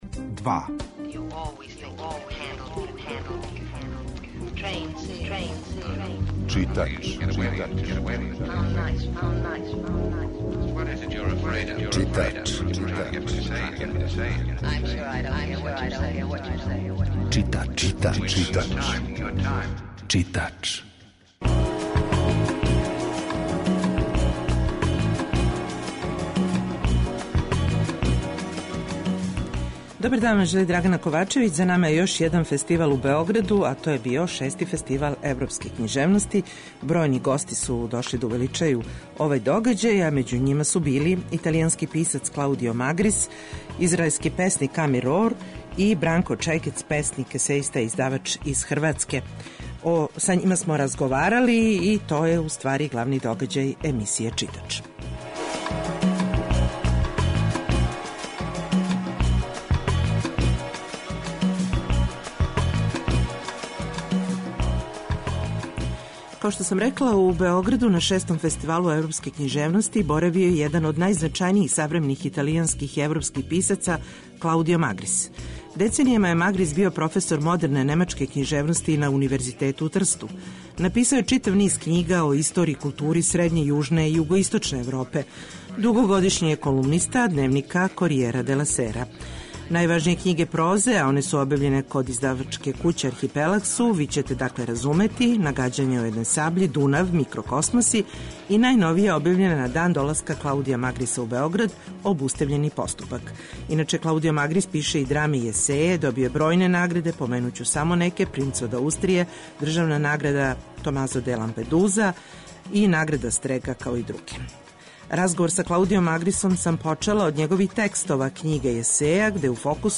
Данашње издање емисије посвећено је разговорима са писцима из света који су боравили протекле недеље у Београду.